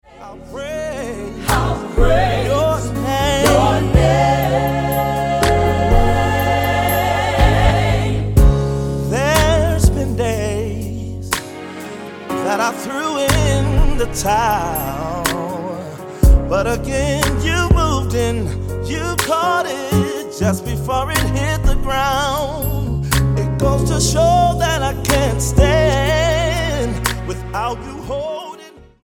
STYLE: Gospel